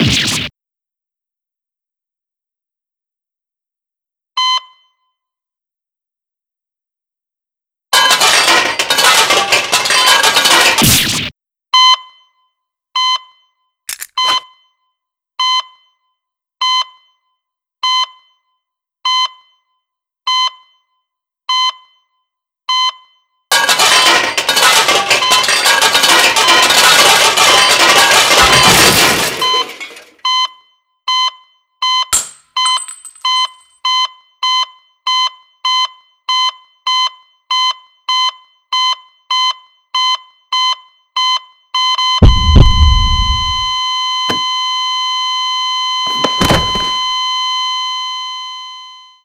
breakdown.wav